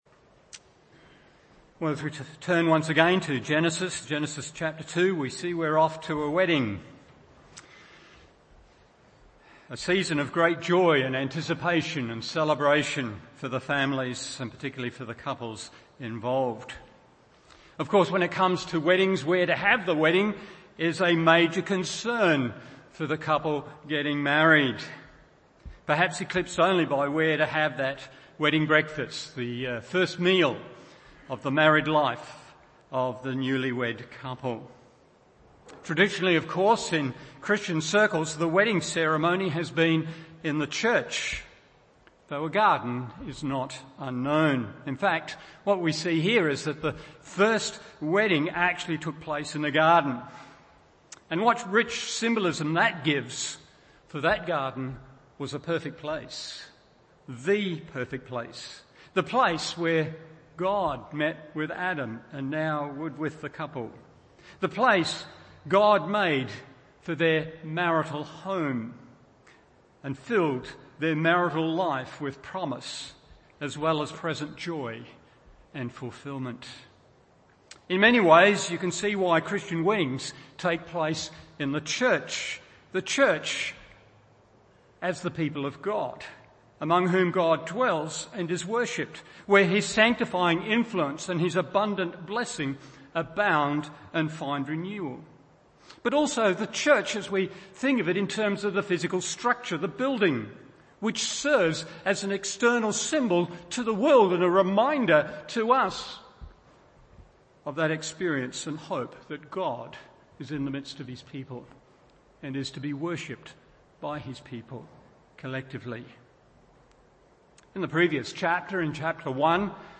Morning Service Genesis 2:18-25 1.